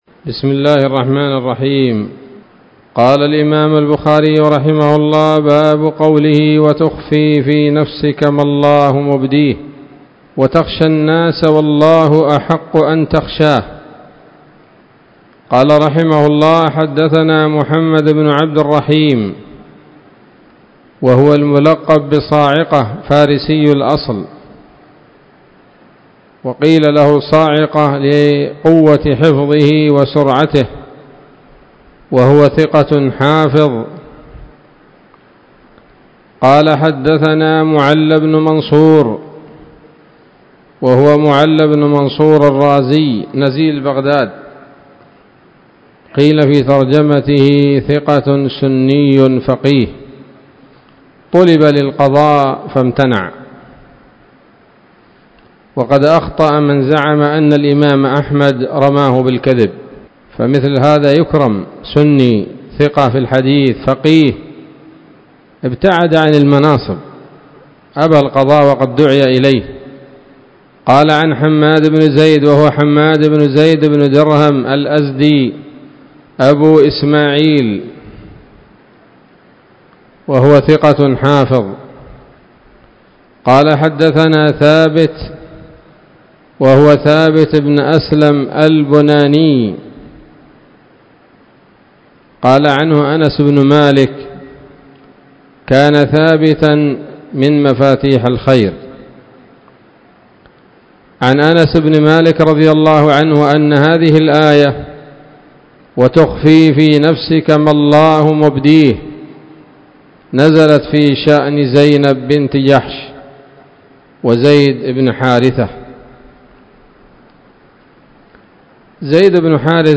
الدرس الرابع بعد المائتين من كتاب التفسير من صحيح الإمام البخاري